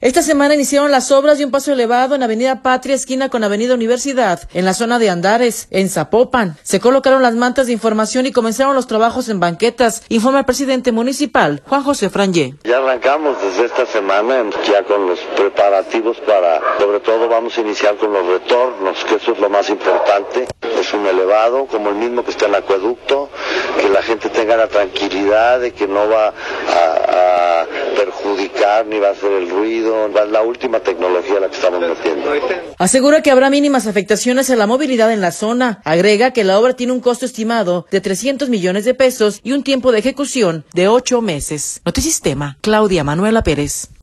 Esta semana iniciaron las obras de un paso elevado en avenida Patria esquina con avenida Universidad, en la zona de Andares, en Zapopan. Se colocaron las mantas de información y comenzaron los trabajos en banquetas, informa el presidente municipal, Juan José Frangie.